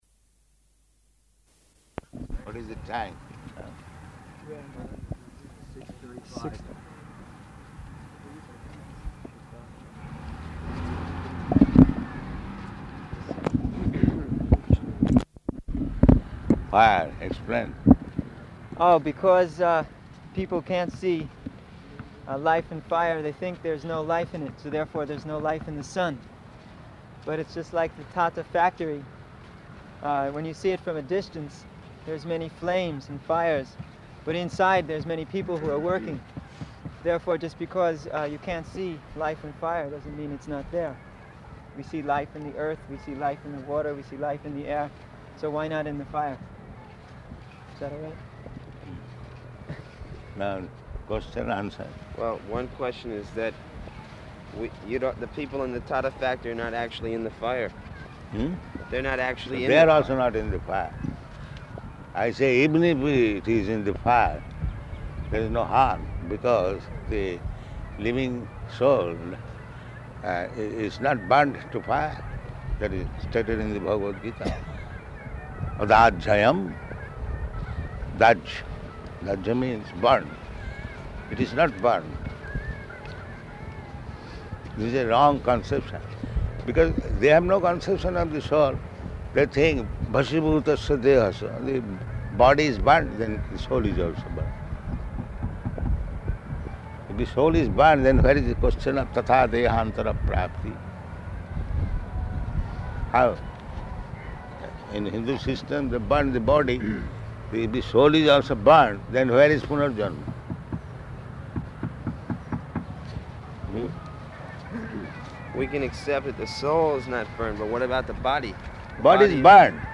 Morning Walk --:-- --:-- Type: Walk Dated: January 2nd 1976 Location: Madras Audio file: 760102MW.MAD.mp3 Prabhupāda: What is the time now?